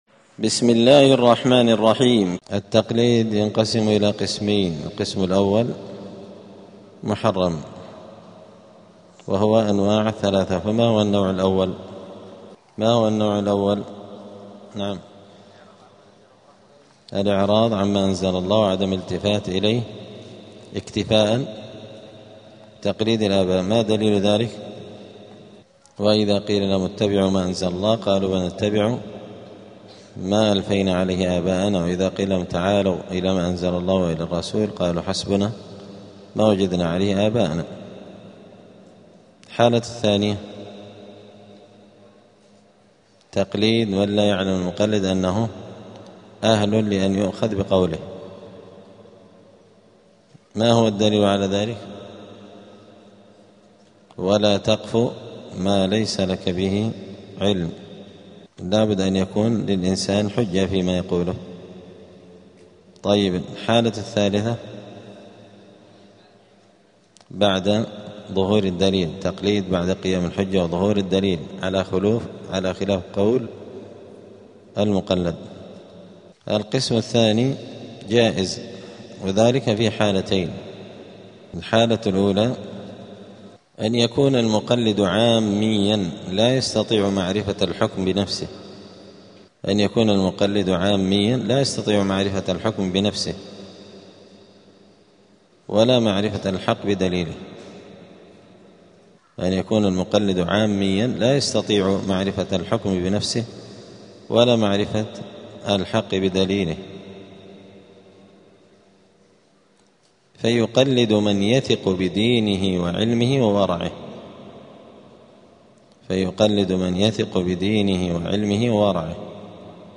دار الحديث السلفية بمسجد الفرقان قشن المهرة اليمن
الأربعاء 18 رجب 1447 هــــ | الدروس، الفصول للمبتدئين في علم الأصول، دروس الفقة و اصوله | شارك بتعليقك | 13 المشاهدات